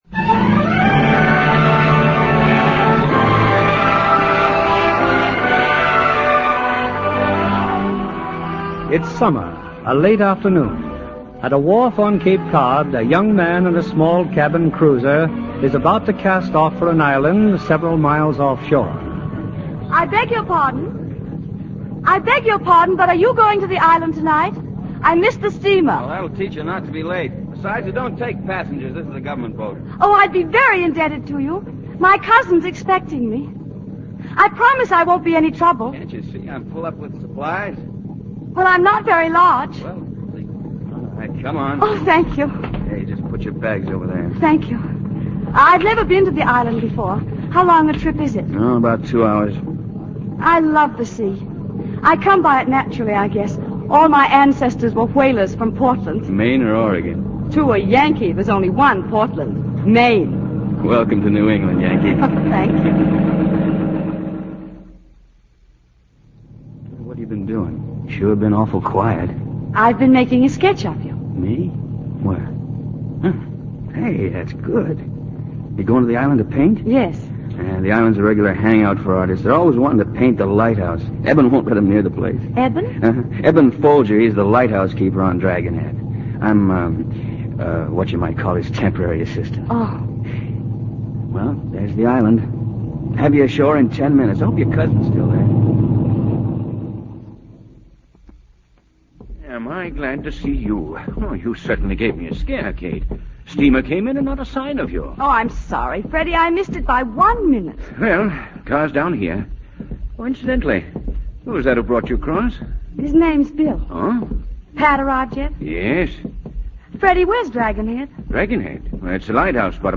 A Stolen Life, starring Bette Davis, Glenn Ford